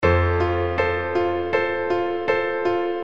描述：解释：！创造你的钢琴！这里有所有的C调钢琴和弦。这里有所有C大调的钢琴和弦，用这些曲子你可以创造你自己的钢琴曲。构建你喜欢的钢琴旋律。玩得开心 )
Tag: 80 bpm Hip Hop Loops Piano Loops 516.84 KB wav Key : Unknown